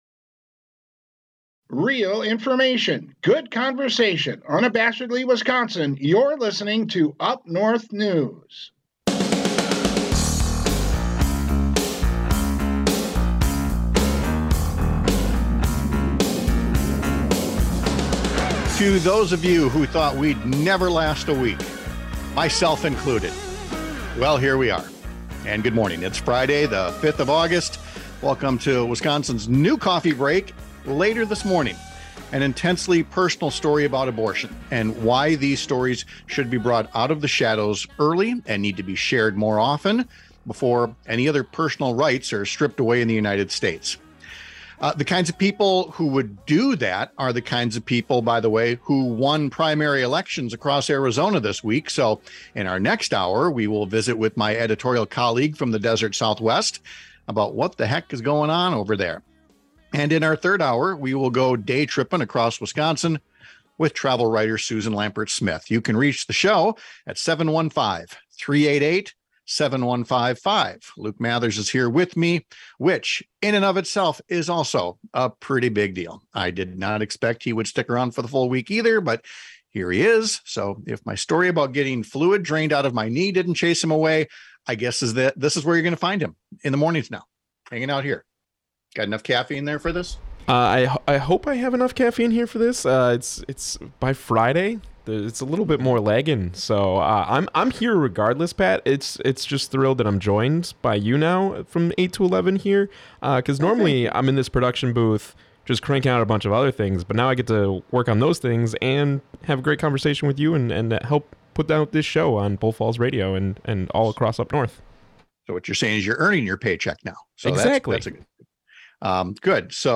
Panel: The Future of Public Education in Wisconsin